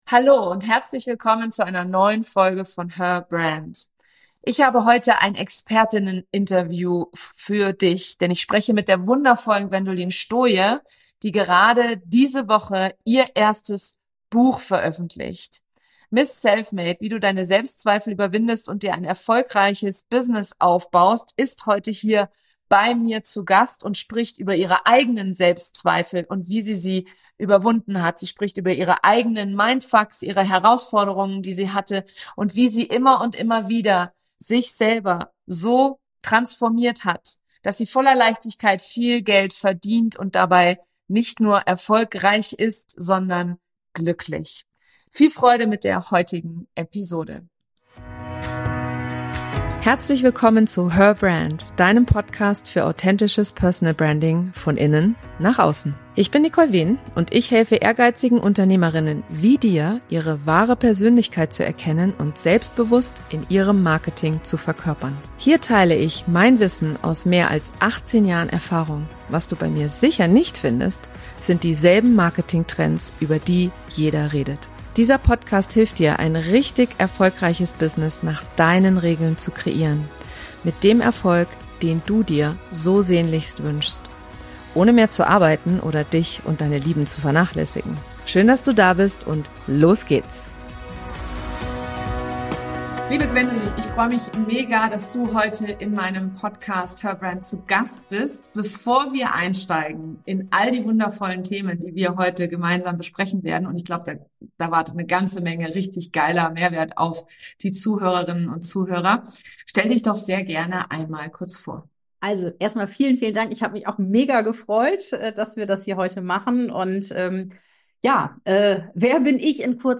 Expertinnen-Interview